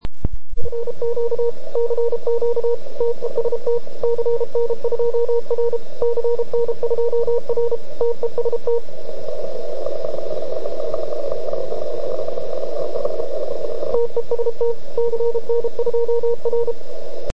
Jak poslouchala FT857?